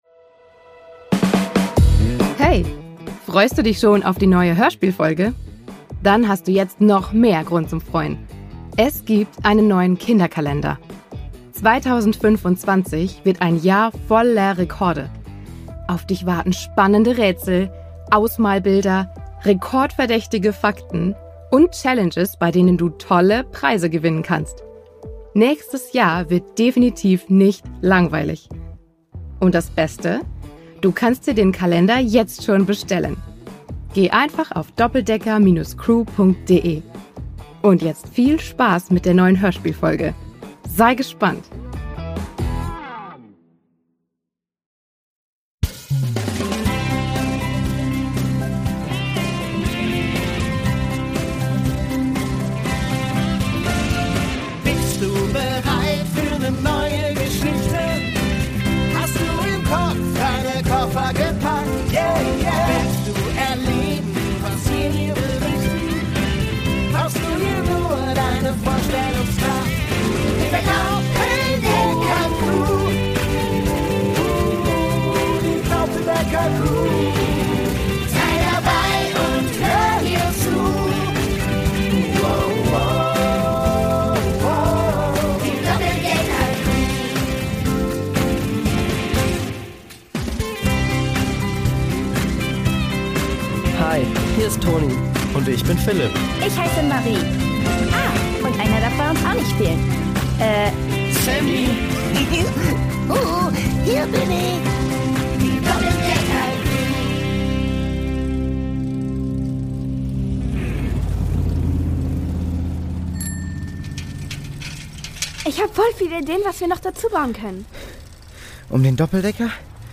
Brasilien 8: Farm in Not (1/2) | Die Doppeldecker Crew | Hörspiel für Kinder (Hörbuch) ~ Die Doppeldecker Crew | Hörspiel für Kinder (Hörbuch) Podcast